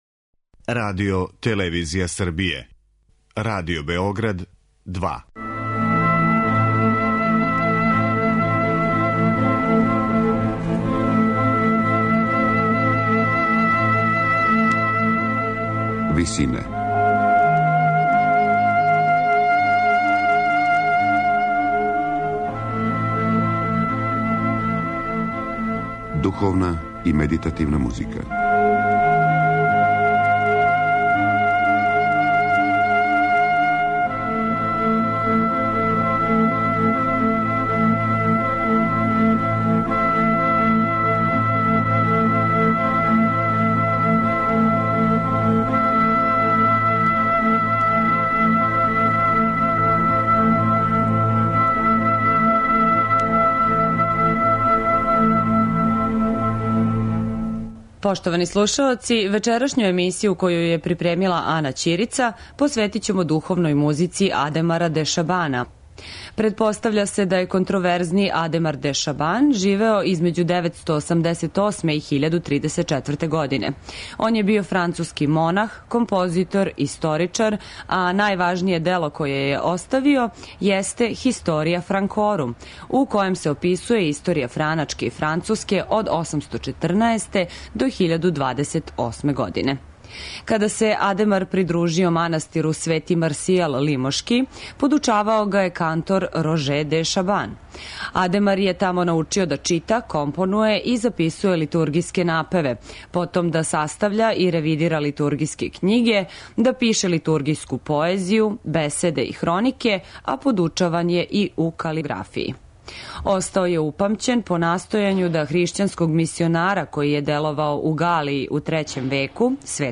Духовна музика Адемара де Шабана